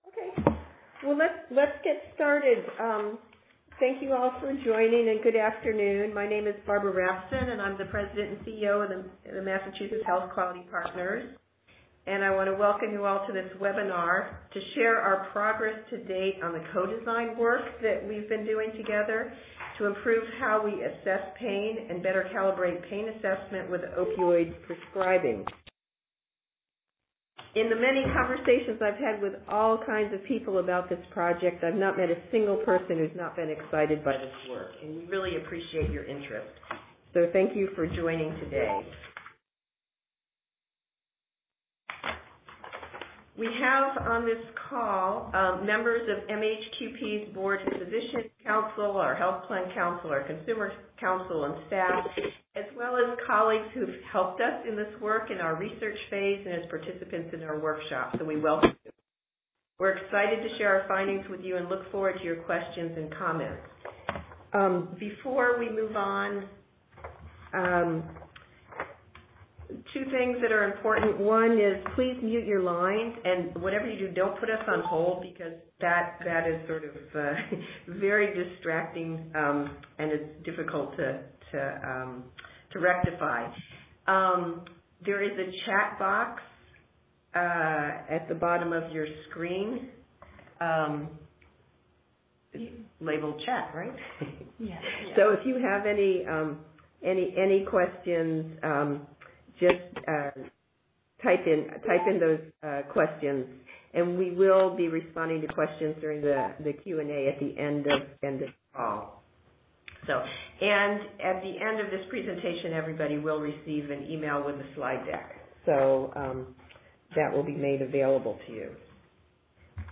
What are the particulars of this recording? In a webinar on November 28, 2017, MHQP and its partners at Mad*Pow’s Center for Health Experience Design shared their progress to date on our co-design approach to improve pain assessment and better calibrate pain assessment with opioid prescribing.